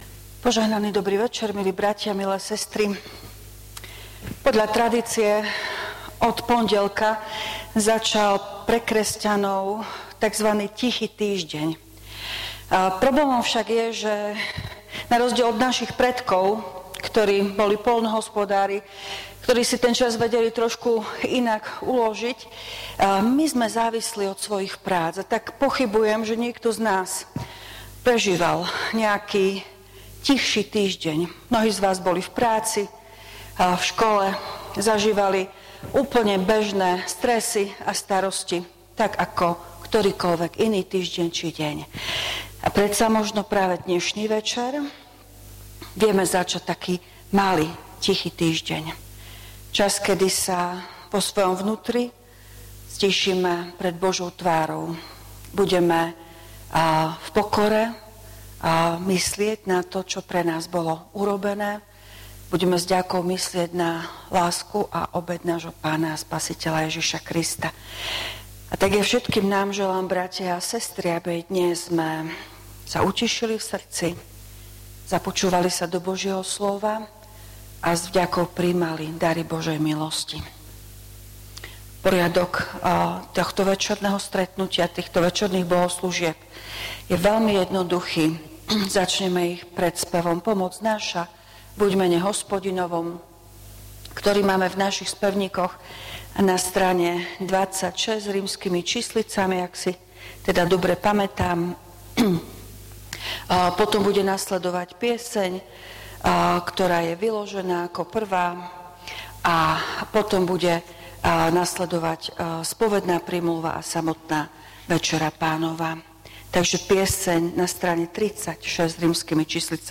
Služby Božie – Zelený štvrtok
V nasledovnom článku si môžete vypočuť zvukový záznam zo služieb Božích – Zelený štvrtok.